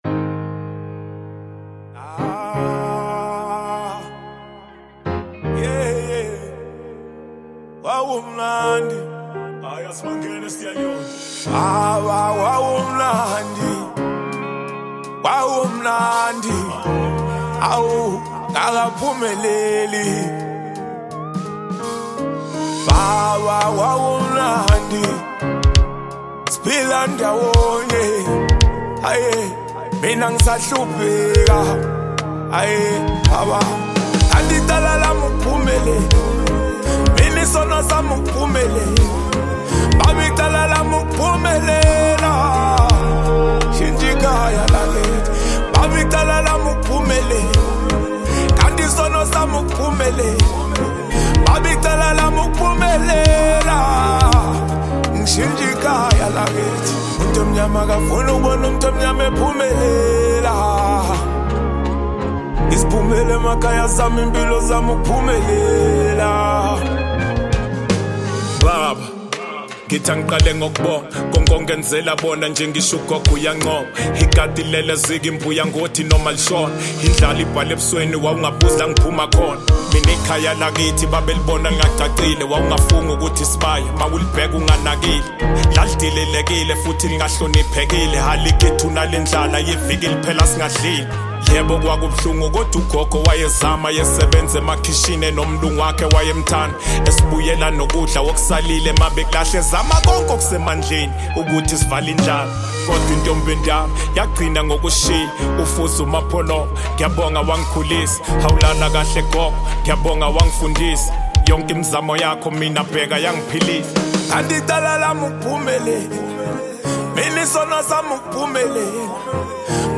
South African rapper